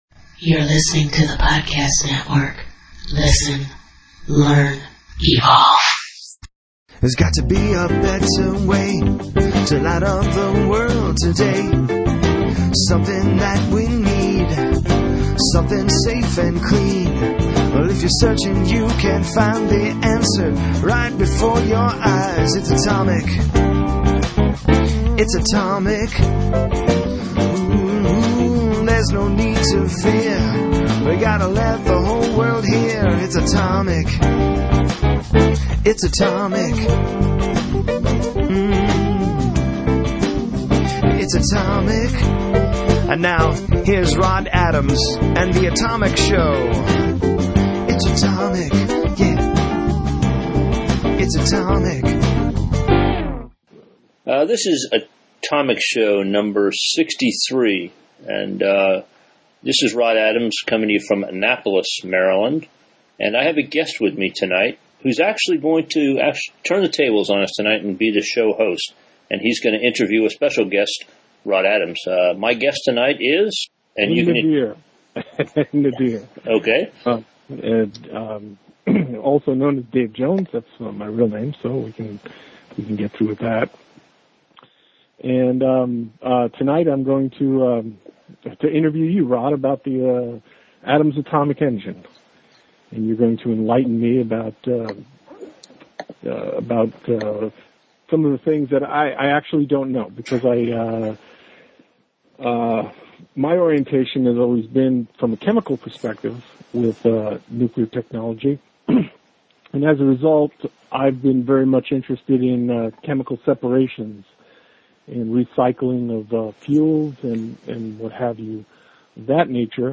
Several listeners and recent guests have expressed an interest in learning more about the design, so I thought it might be more interesting and fun to have one of them interview me than to have me read from some of our sales and marketing literature.